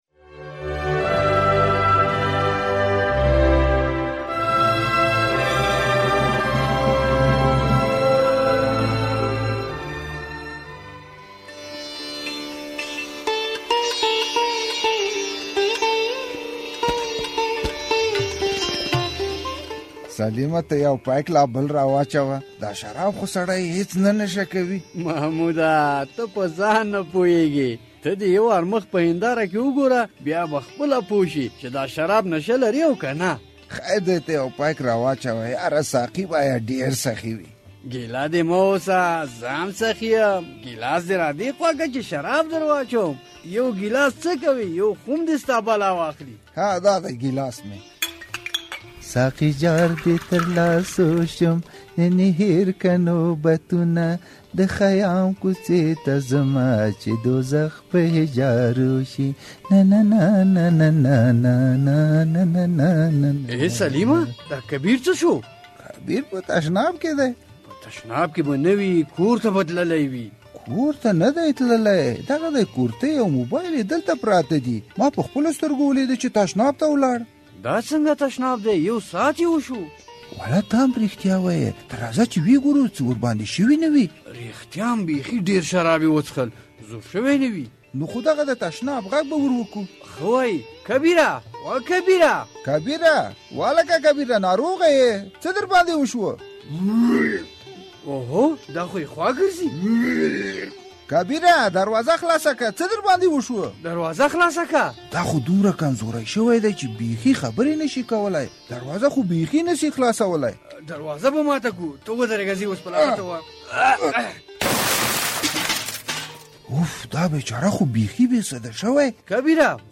د زهرو کاروان ډرامه؛ کبير ولې ناڅاپه ناروغ شو؟